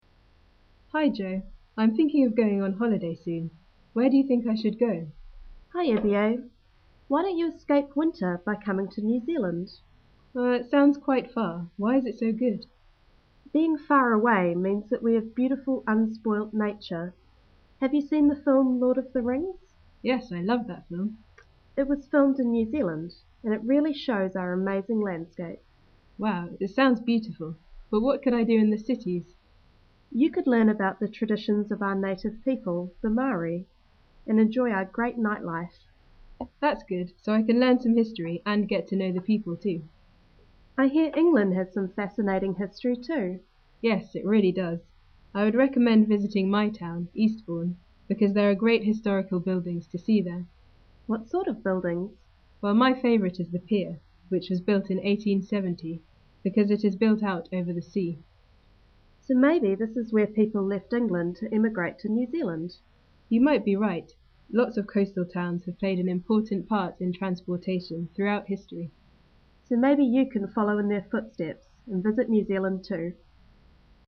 Nouvelle Zélande / Grande Bretagne